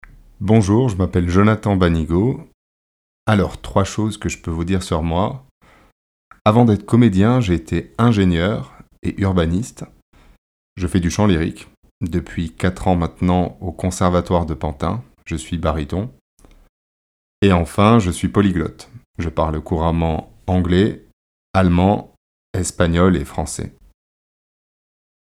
Voix off
30 - 45 ans - Baryton